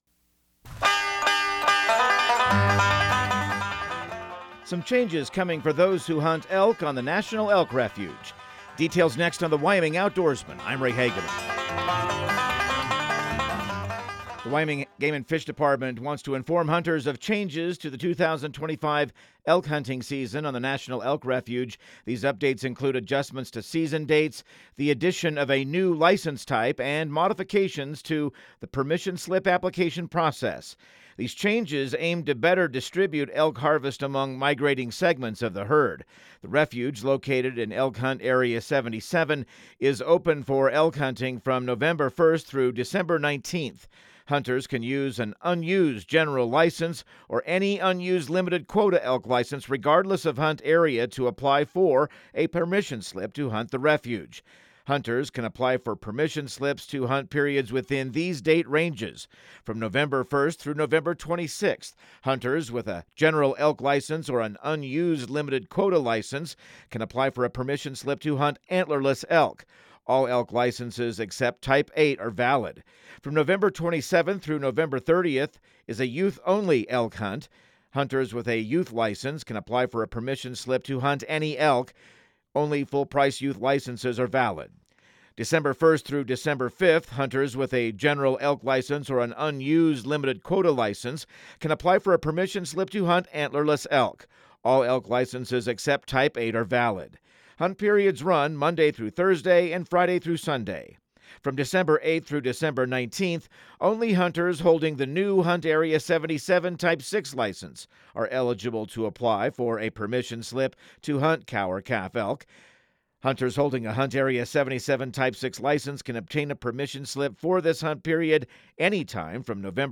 Radio news | Week of July 21